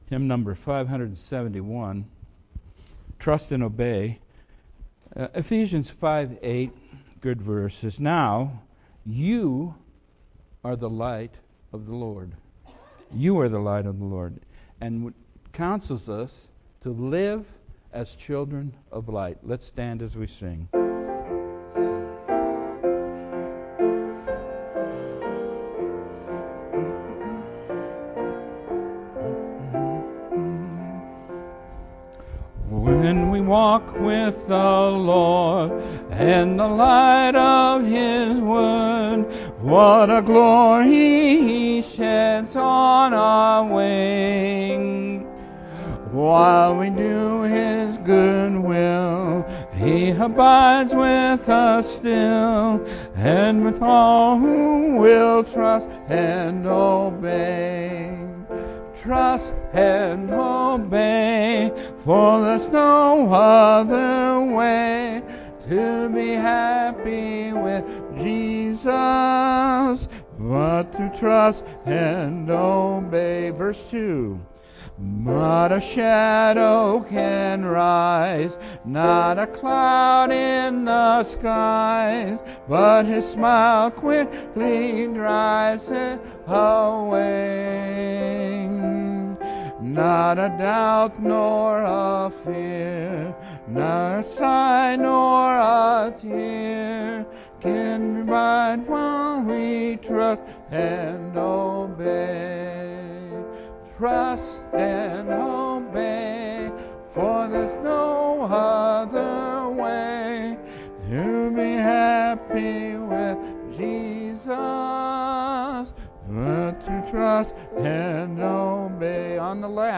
Audio Sermon Only